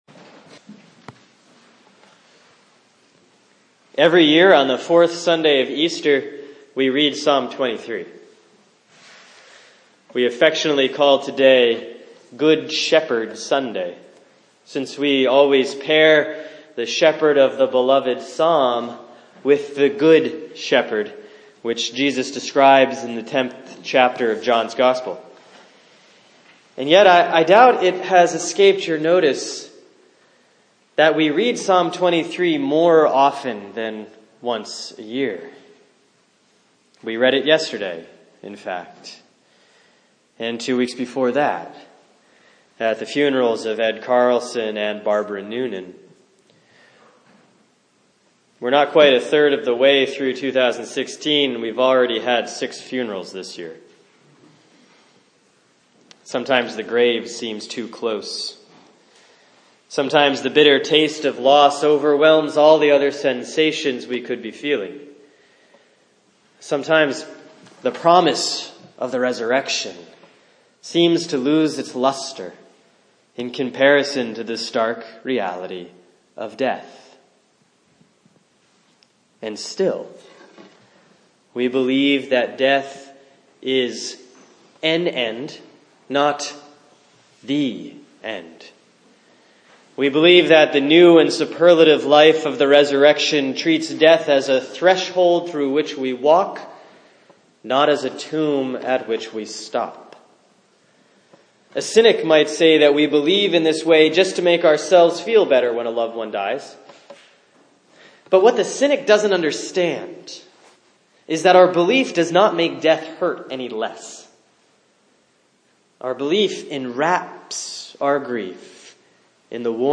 Sermon for Sunday, April 17, 2016 || Easter 4C || Psalm 23; Revelation 7:9-17